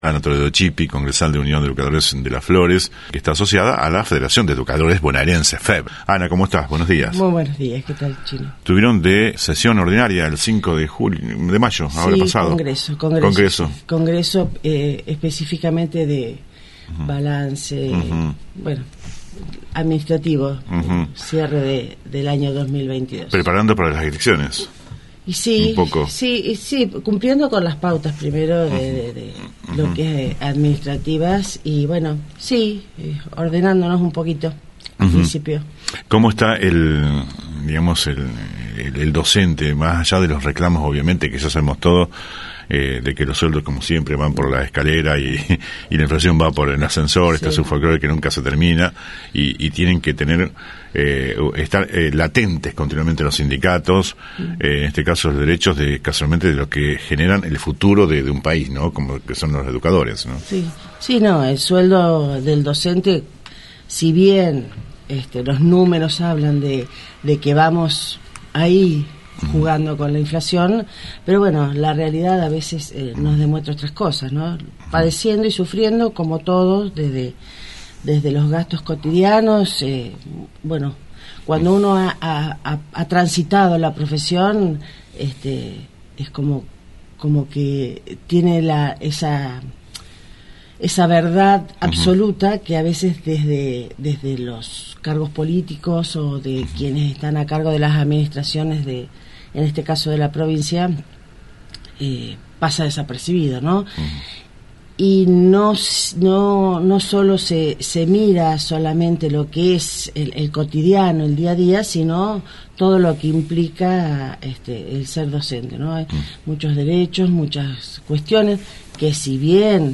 visitó los estudios de la radio